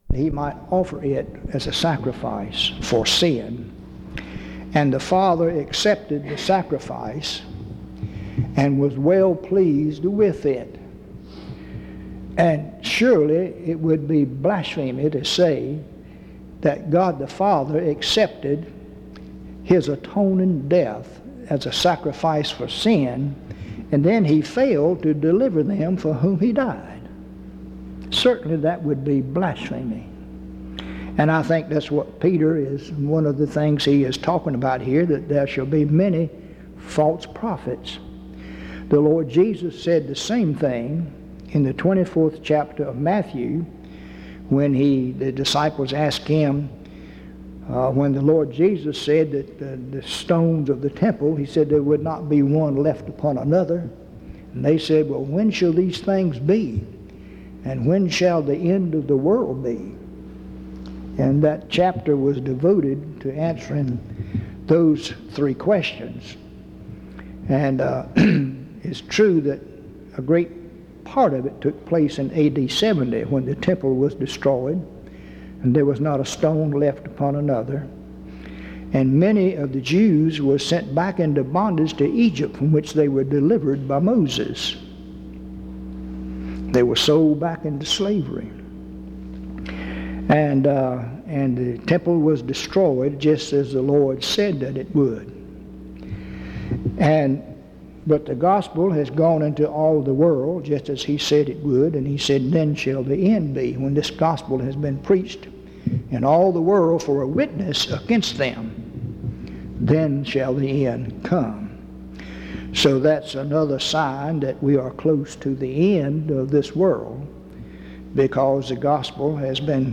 2 Peter 1:16-2:10; Mother's Day
In Collection: Reidsville/Lindsey Street Primitive Baptist Church audio recordings Thumbnail Titolo Data caricata Visibilità Azioni PBHLA-ACC.001_071-B-01.wav 2026-02-12 Scaricare PBHLA-ACC.001_071-A-01.wav 2026-02-12 Scaricare